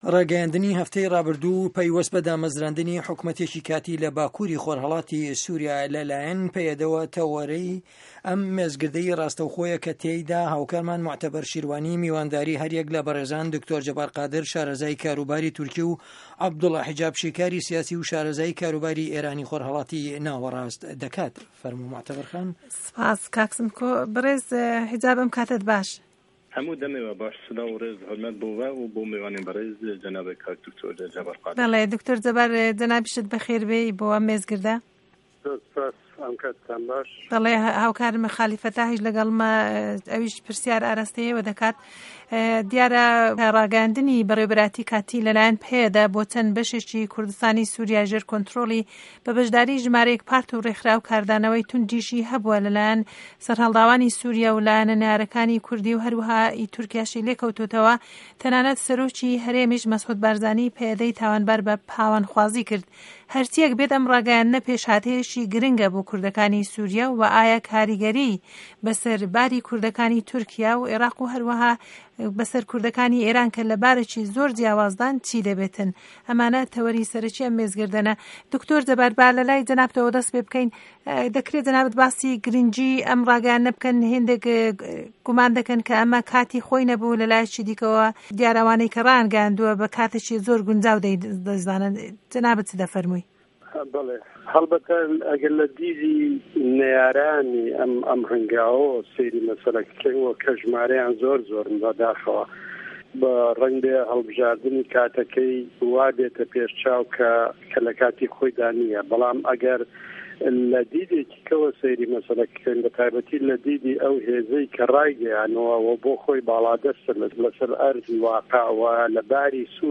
مێزگرد: هه‌ڵسه‌نگاندنی بڕیاری ڕاگه‌یاندنی به‌ڕێوه‌به‌رایه‌تی کوردی له‌ سوریا